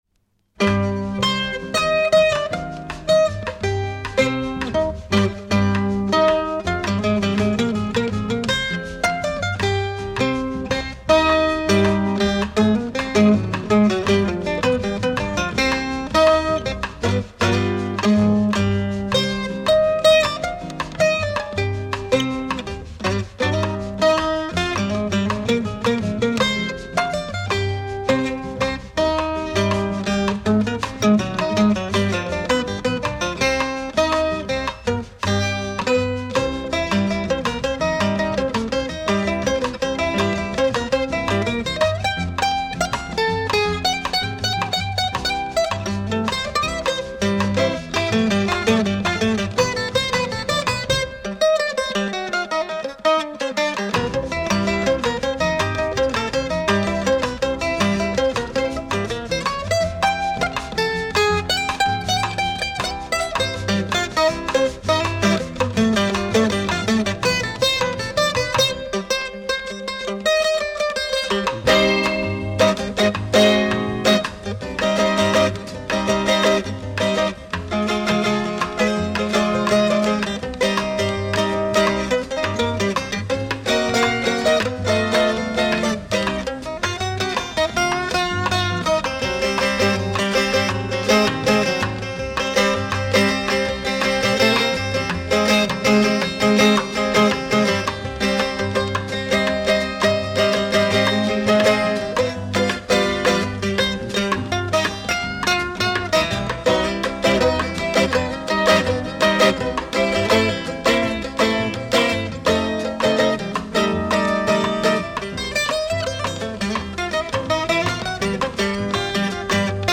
Fine Bossa samba acoutic guitar